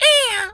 bird_tweety_hurt_03.wav